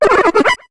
Cri de Tortipouss dans Pokémon HOME.